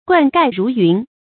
冠蓋如云 注音： ㄍㄨㄢ ㄍㄞˋ ㄖㄨˊ ㄧㄨㄣˊ 讀音讀法： 意思解釋： 官員們的禮帽和車篷密集得像一片云層。